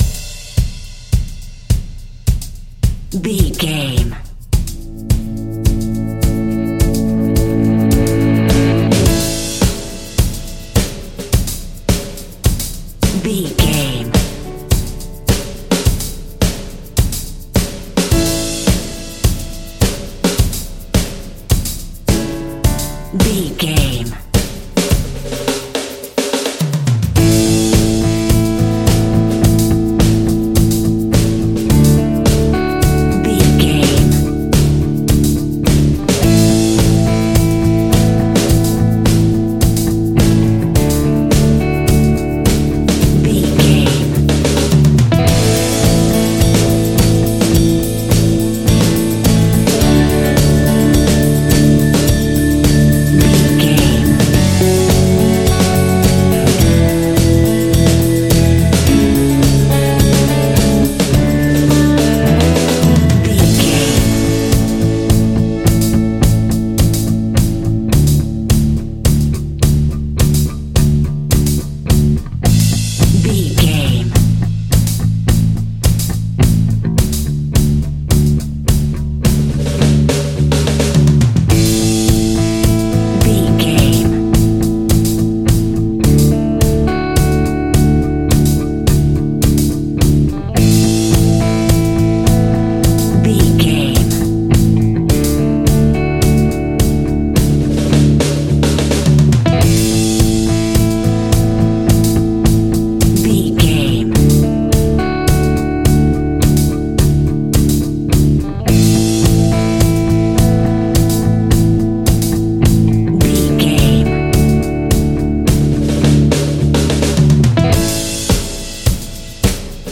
Ionian/Major
groovy
powerful
organ
bass guitar
electric guitar
piano